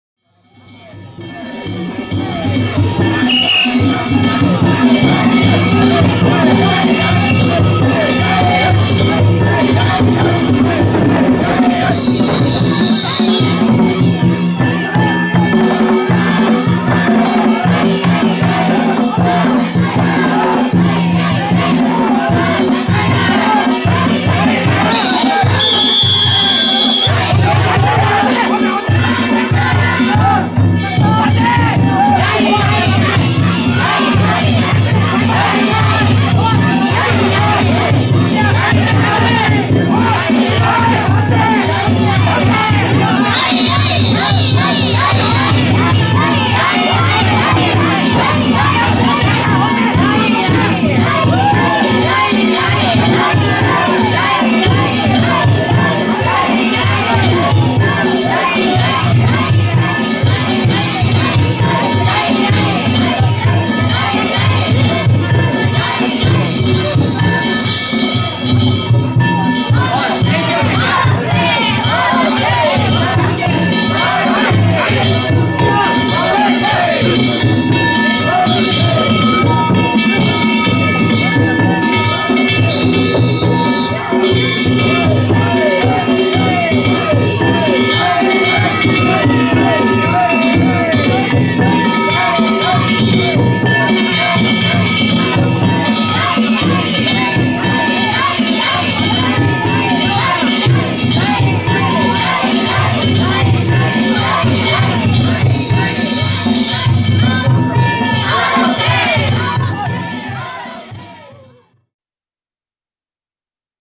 宮前東交差点の中央で後ろを担ぎ上げて
カワイイ声で掛け声♪